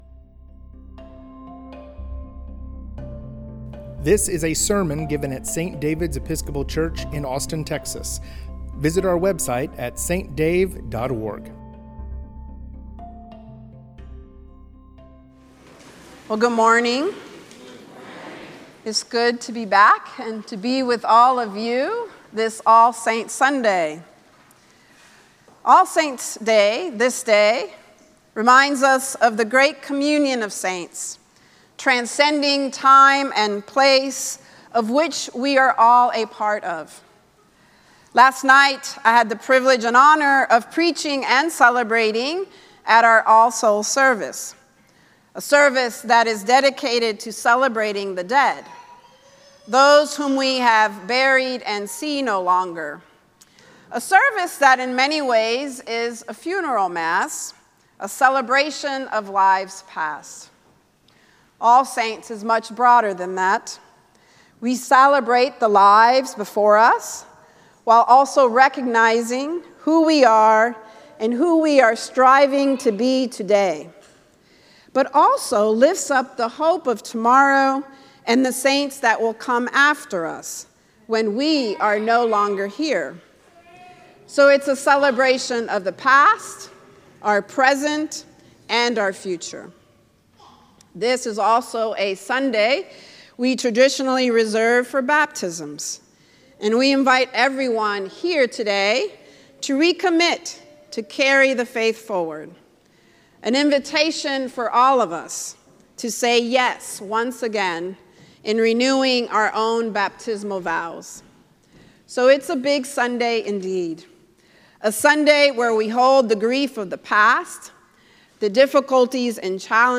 sermon from All Saints' Day.